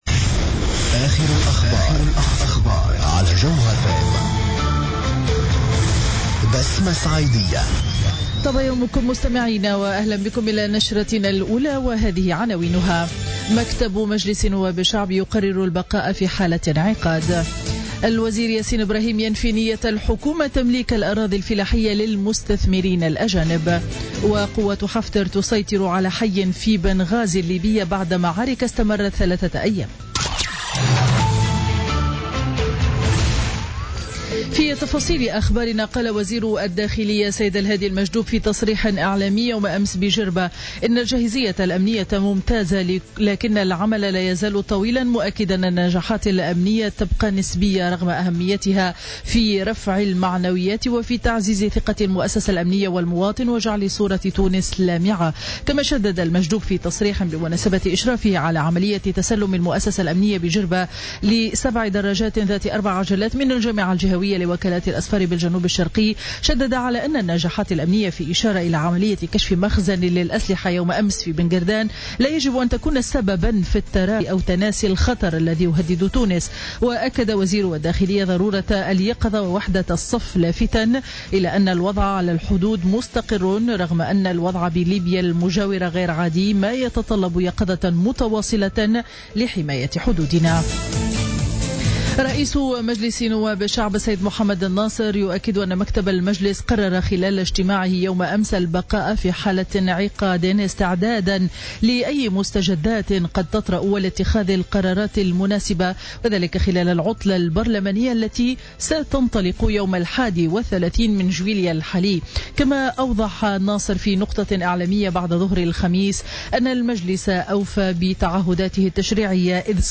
نشرة أخبار السابعة صباحا ليوم الجمعة 29 جويلية 2016